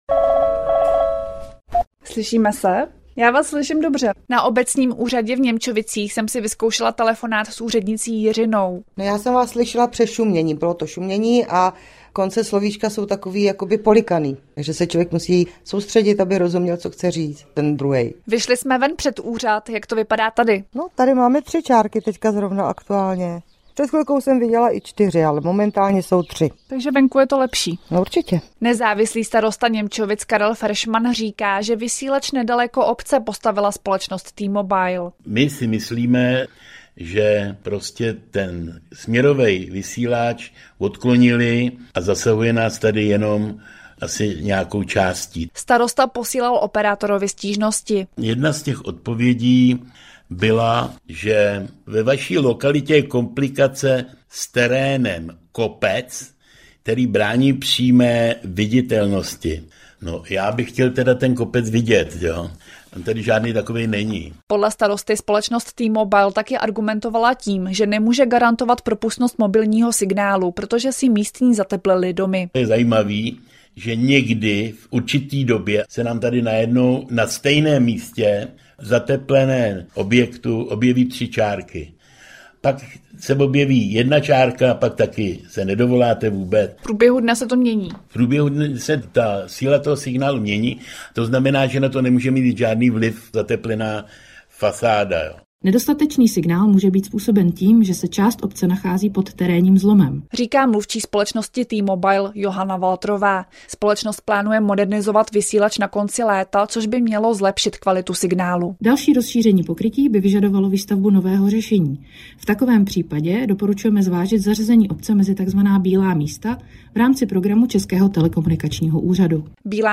Zprávy pro Plzeňský kraj: V Němčovicích mají problém s telefonním signálem, operátor svaluje vinu na terén a zateplení - 22.04.2025